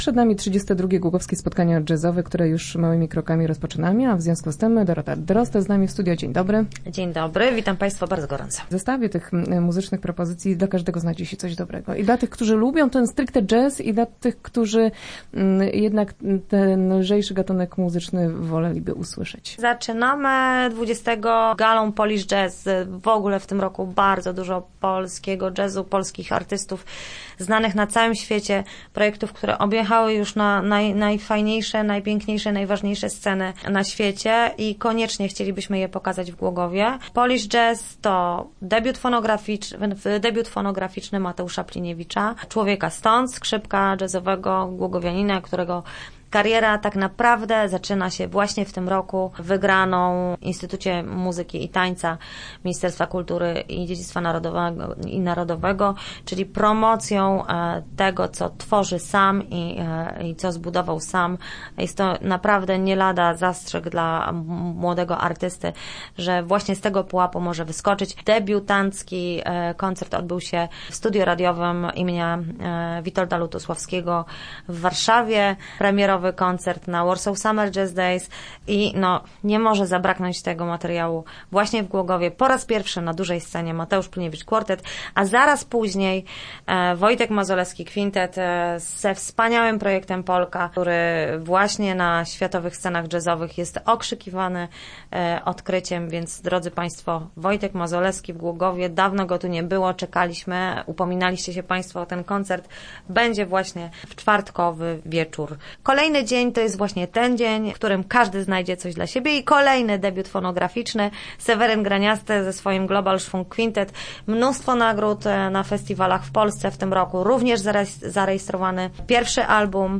Start arrow Rozmowy Elki arrow Jazzowa jesień w Głogowie